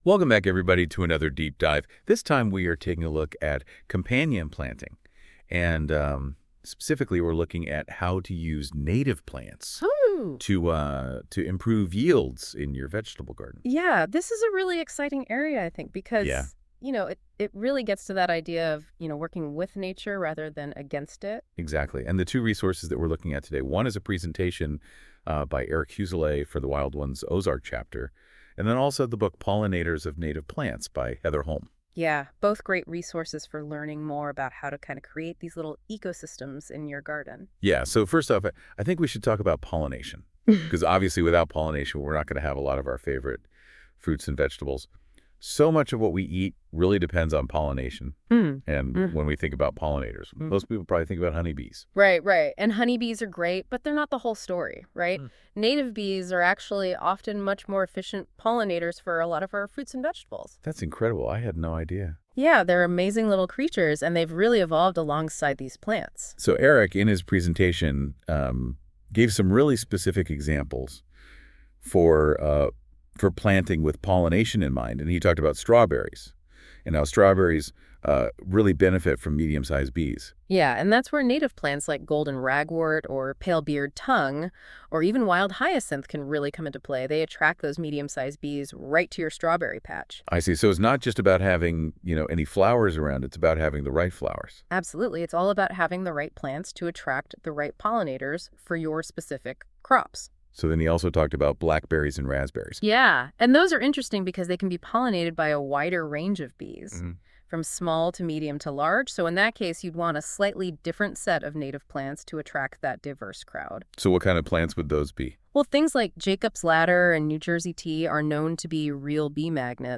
A presentation by an environmental scientist on companion planting using native plants. The speaker emphasizes attracting beneficial insects and pollinators to improve vegetable garden yields.